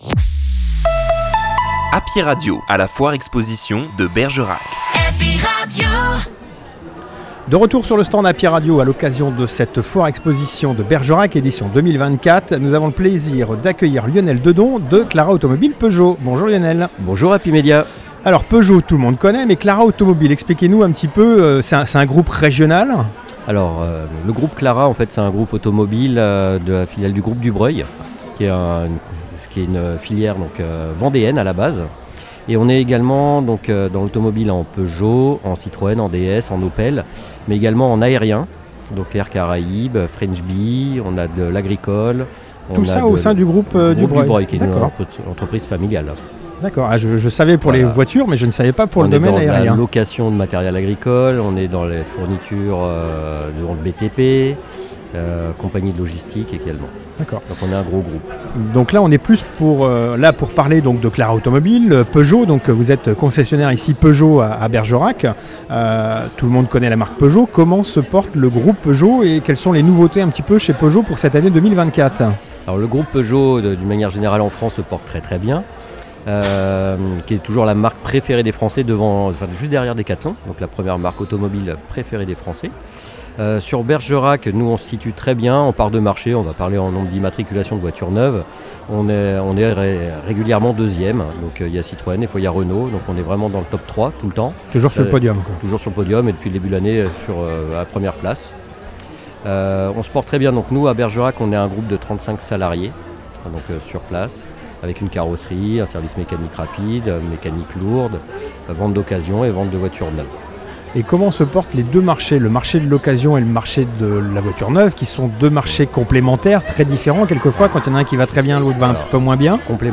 Foire Expo De Bergerac 2024